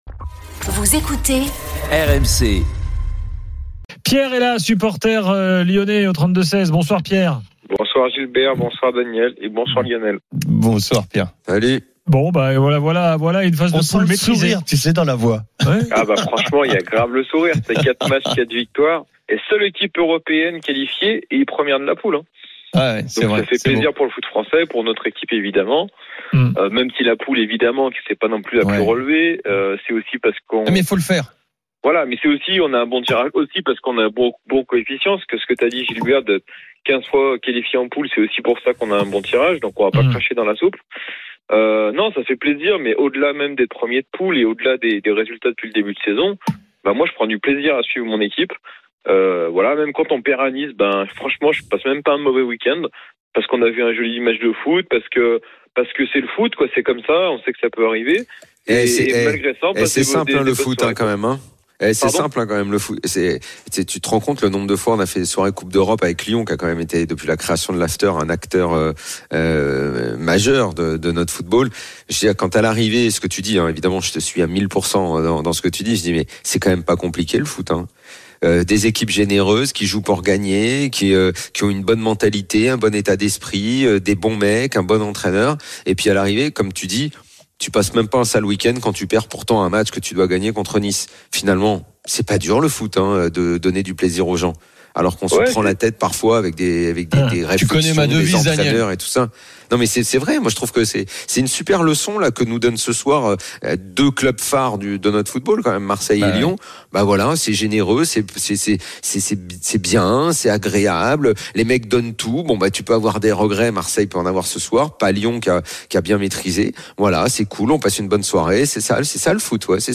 les conférences de presse d'après-match et les débats animés entre supporters, experts de l'After et auditeurs. RMC est une radio généraliste, essentiellement axée sur l'actualité et sur l'interactivité avec les auditeurs, dans un format 100% parlé, inédit en France.